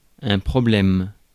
Ääntäminen
France: IPA: [ɛ̃ pʁɔ.blɛm]